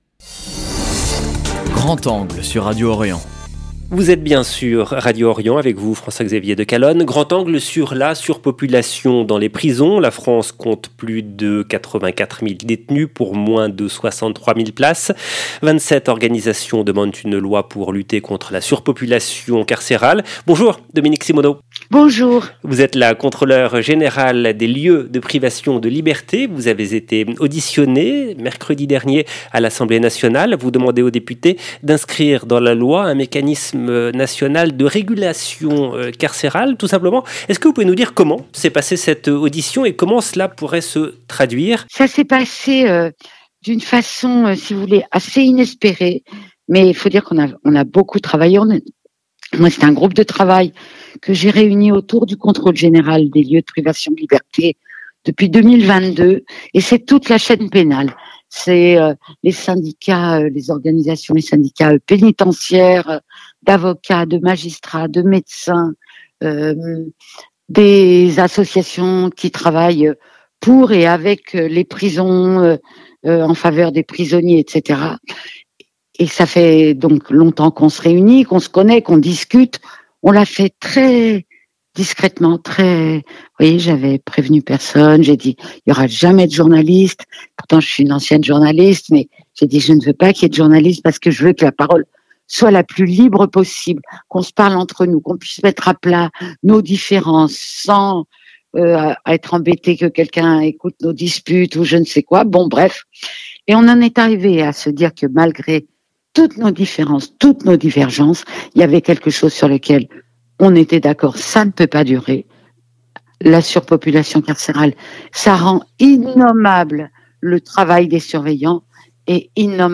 Entretien avec Dominique Simonnot, Controleur général des lieux de privation de liberté. 0:00 10 min 23 sec